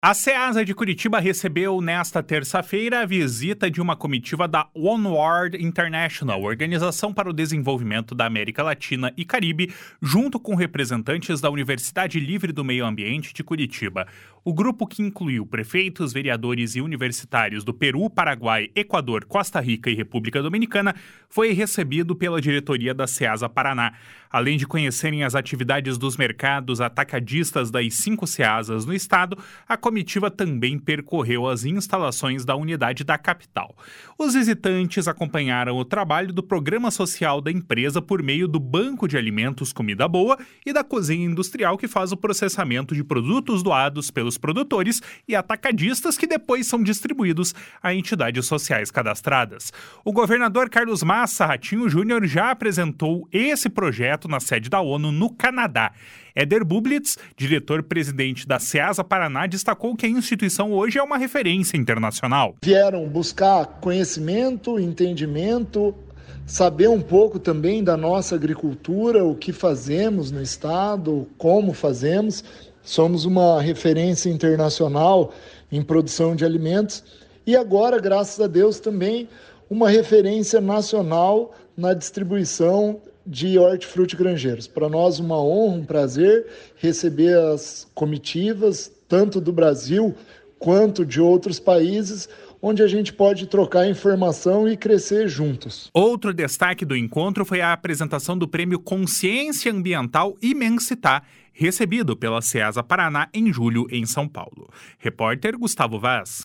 Éder Bublitz, diretor-presidente da Ceasa Paraná, destacou que a instituição hoje é uma referência internacional. // SONORA ÉDER BUBLITZ //